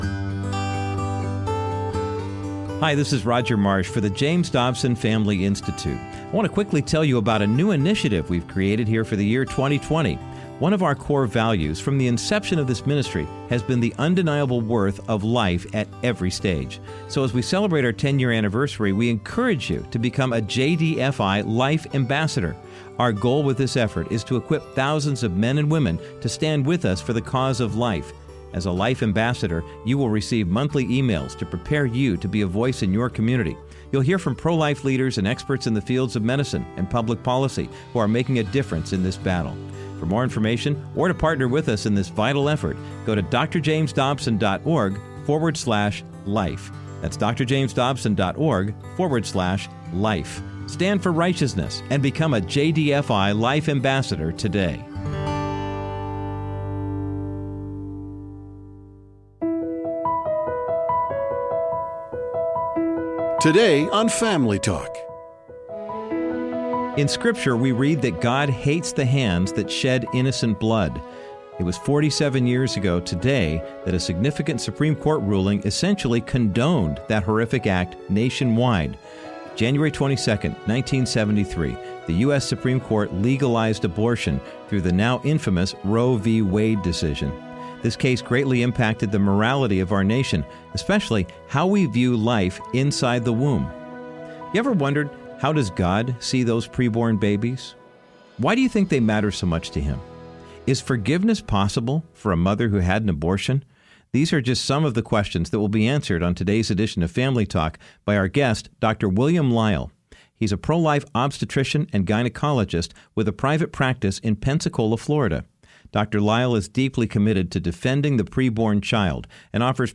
On this day 47 years ago, the U.S. Supreme Court legalized abortion through the infamous Roe v. Wade decision. Todays Family Talk broadcast features Dr. Dobsons conversation with pro-life OB/GYN